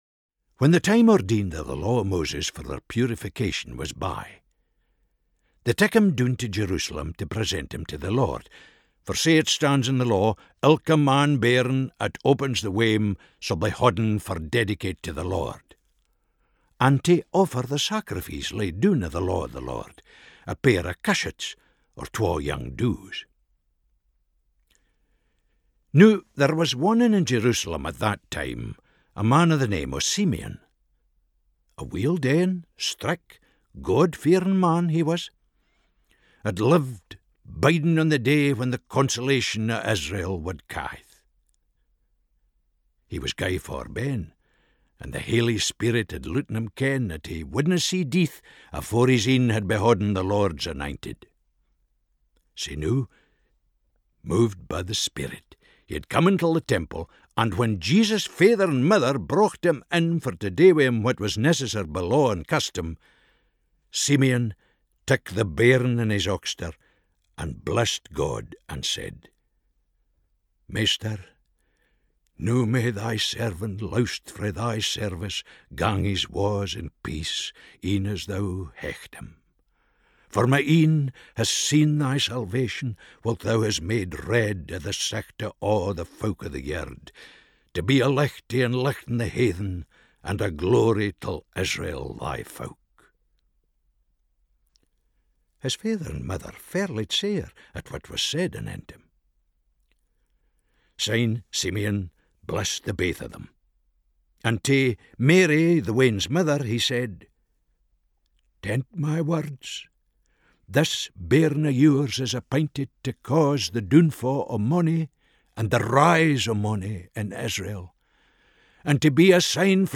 Reading of the New Testament in Scots for 27th December 2020.